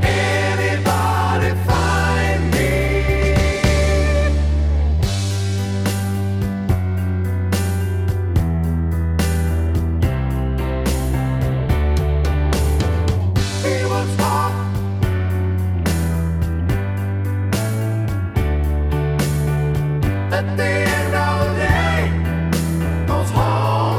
With Claps Down One Semitone Rock 4:59 Buy £1.50